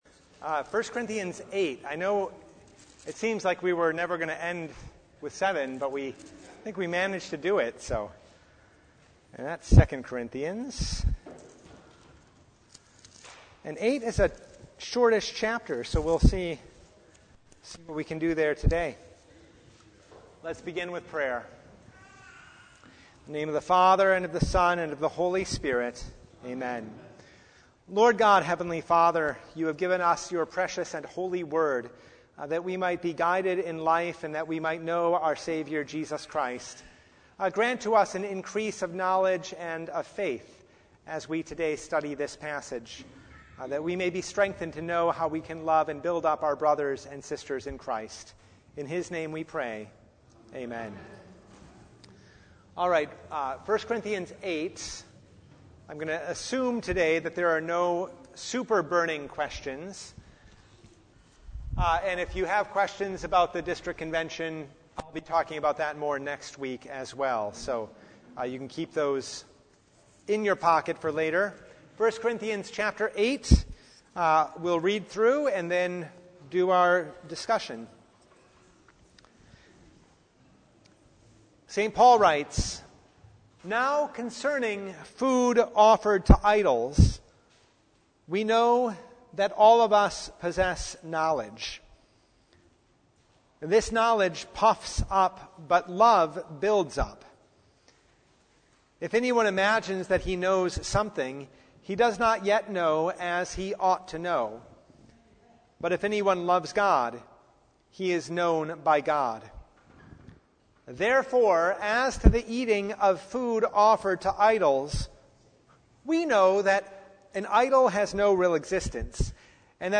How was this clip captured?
1 Corinthians 8:1-13 Service Type: The Feast of the Holy Trinity Topics: Bible Study